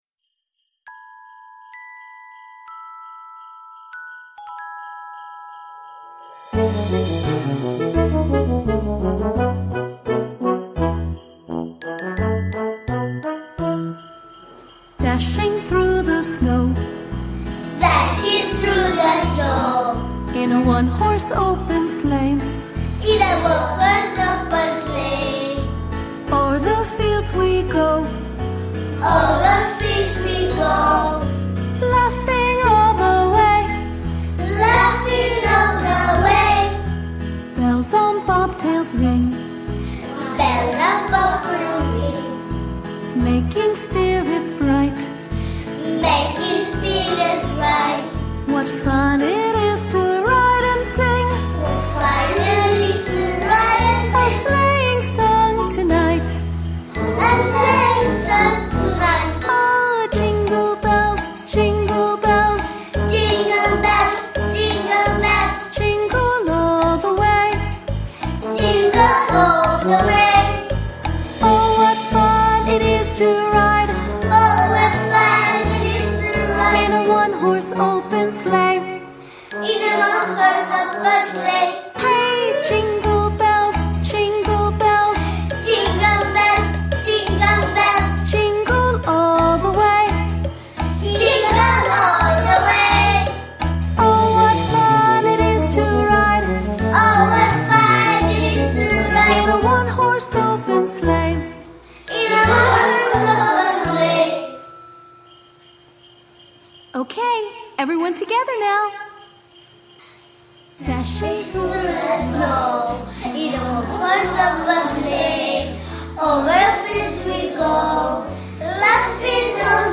Els nens i nenes més grans de P5 van cantar la cançó “JINGLE BELLS”, i també van fer una